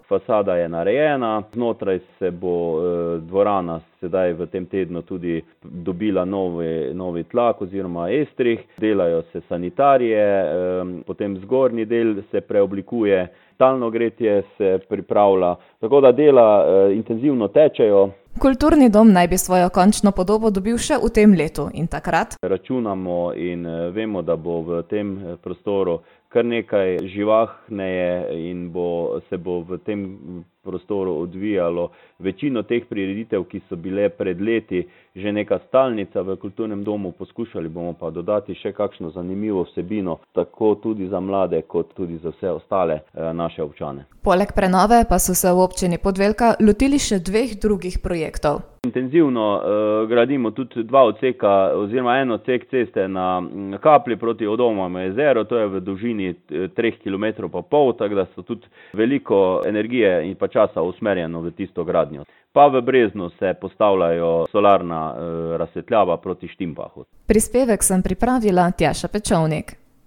Župan Občine Podvelka Miran Pušnik je povedal, da dela tečejo kot po načrtu: